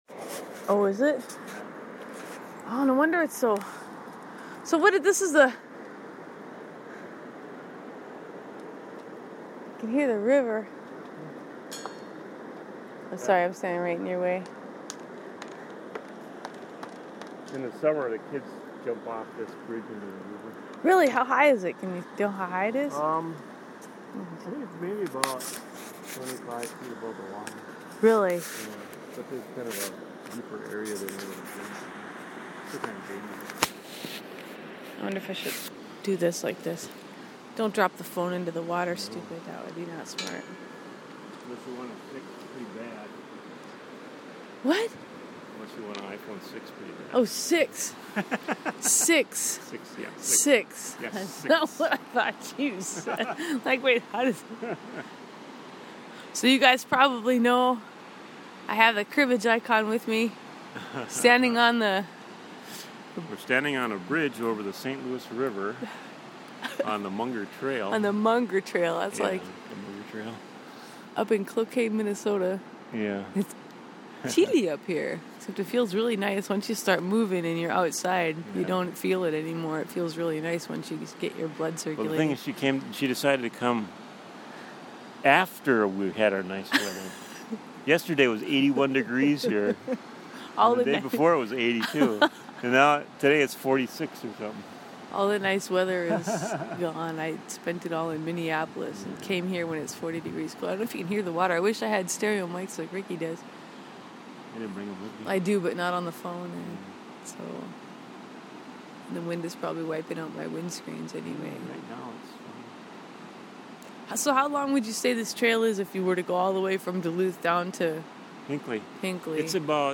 Up The Monger Trail Onto The Bridge Spanning The St. Louis River At Jay Cooke State Park In Northern Minnesota